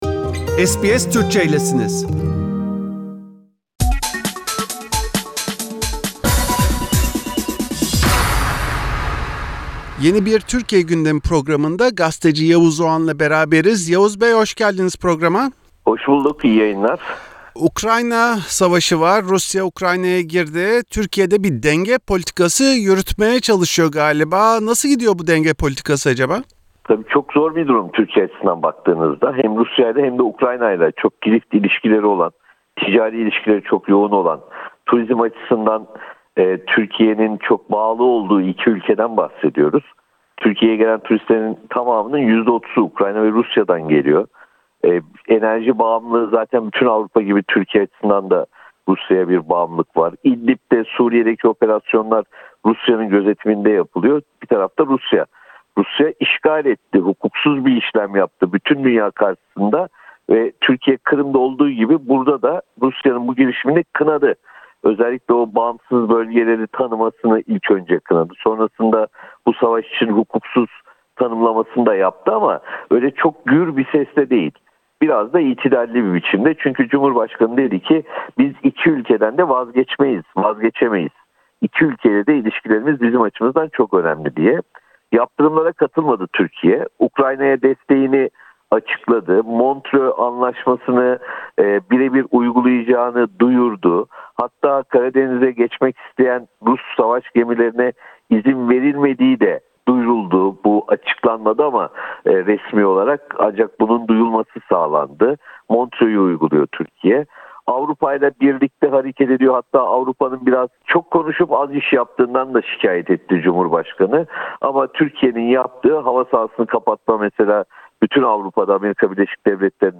Rusya’nın Ukrayna’yı işgali üzerine Türkiye’de hükümet Avrupalı müttefiklerinin yaptıklarını yetersiz bulurken önemli iki ekonomik ortağı arasında hassas bir denge kurma peşinde. Gazeteci Yavuz Oğhan SBS Türkçe için Türkiye gündemini değerlendirdi.